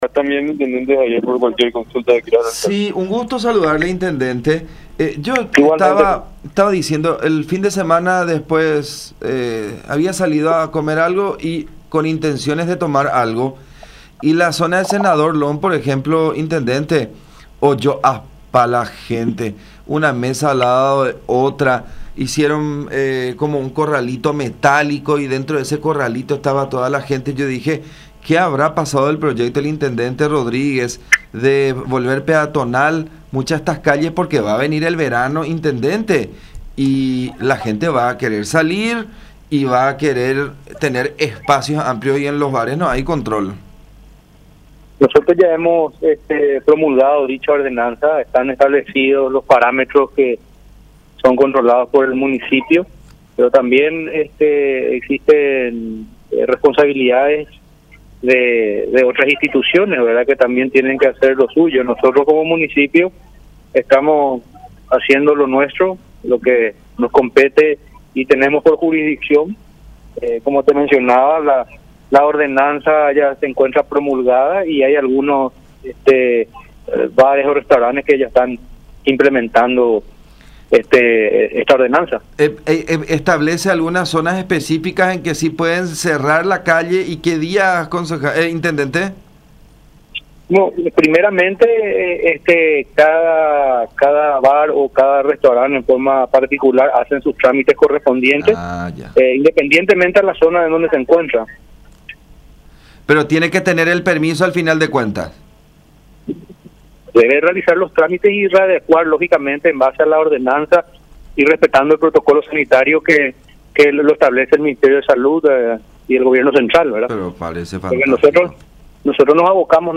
“Hemos promulgado la ordenanza y están establecidos los parámetros que sirven de control por parte del municipio, pero las demás instituciones también deben hacer su parte y tienen sus responsabilidades. Hay algunos bares y restaurantes que ya aplican esta ordenanza respetando el protocolo del Ministerio de Salud”, dijo Rodríguez en comunicación con La Unión.